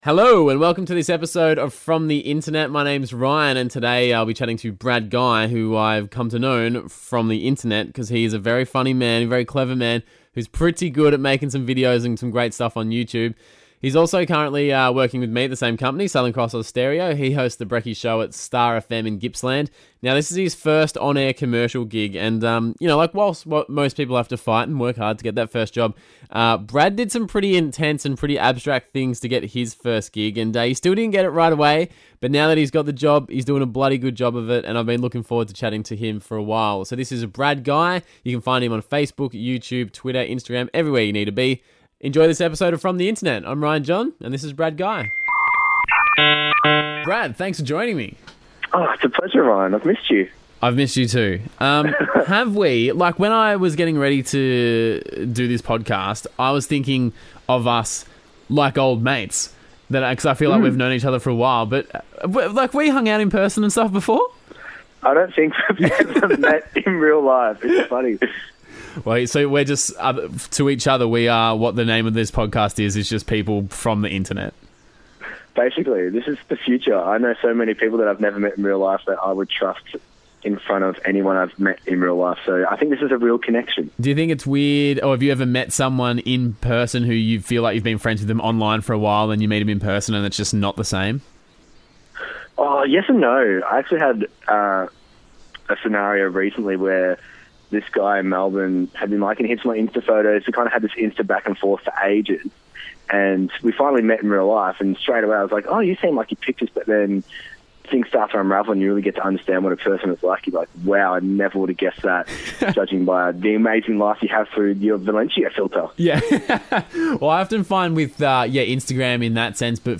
A chat with YouTuber and Radio Announcer